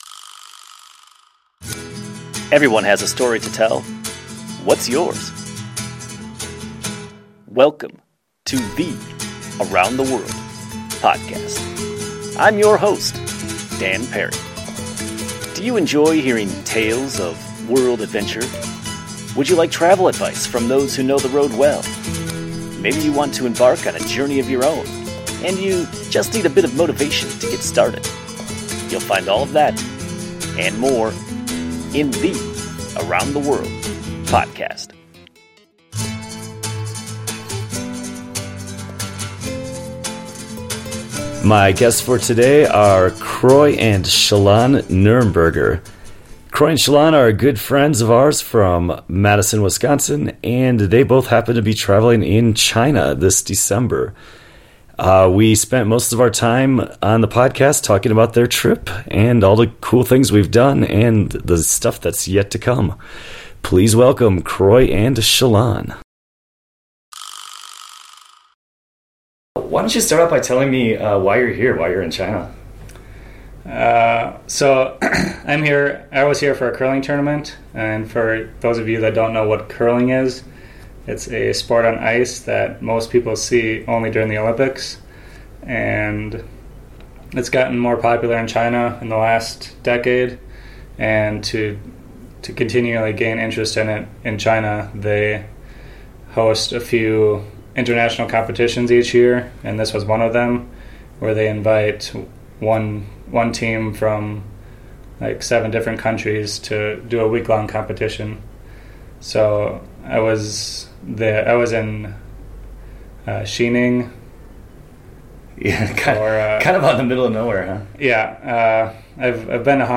We recorded this podcast from Xi'An, just before we split up.